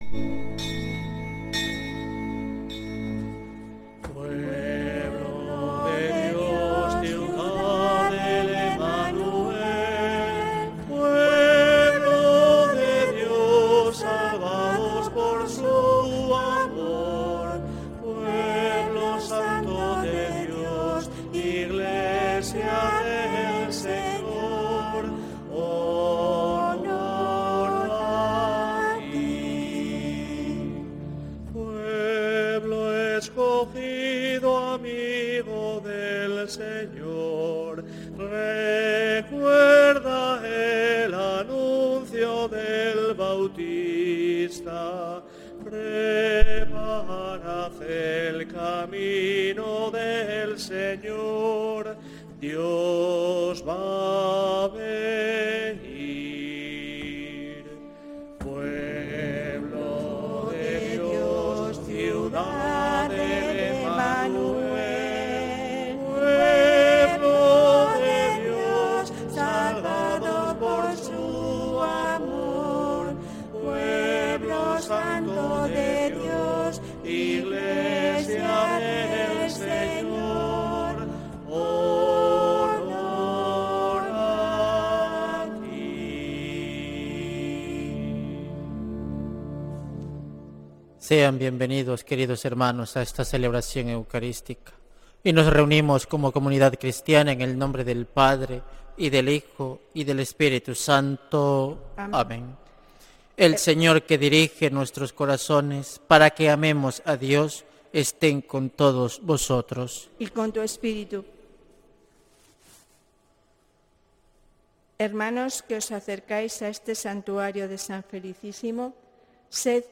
Santa Misa desde San Felicísimo en Deusto, domingo 8 de febrero de 2026